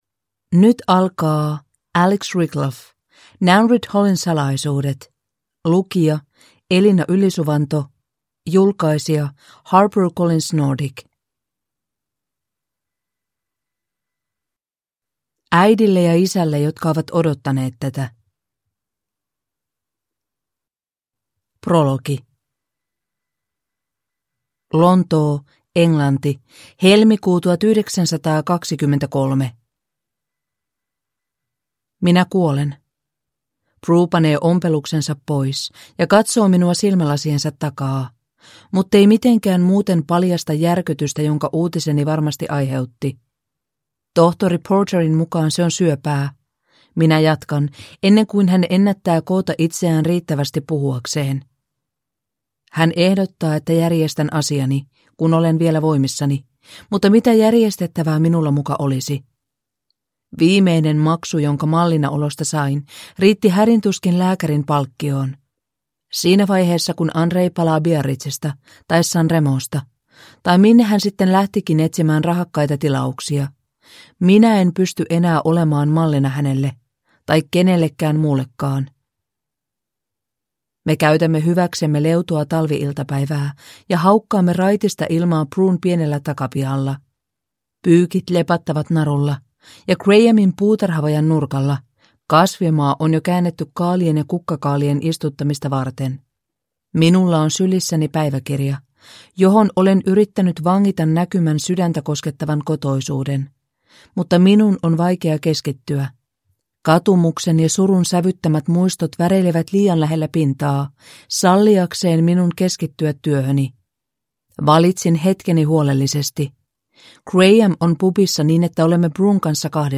Nanreath Hallin salaisuudet (ljudbok) av Alix Rickloff